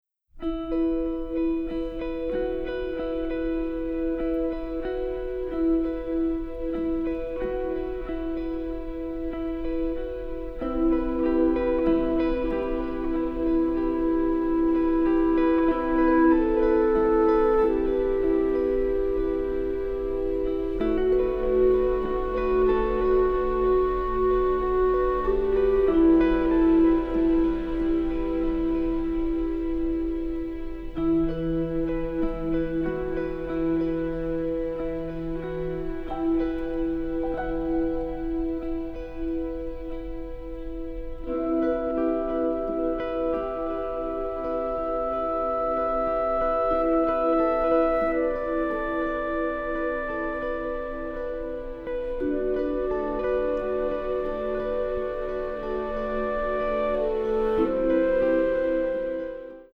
encompassing tenderness, sadness and nostalgia